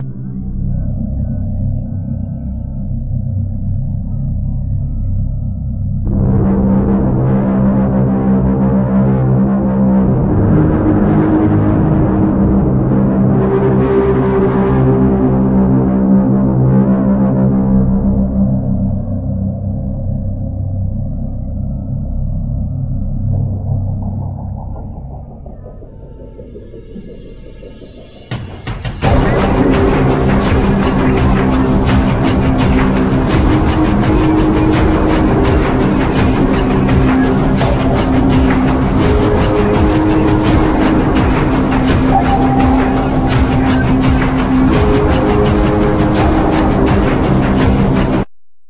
Electronics,Percussion,Voice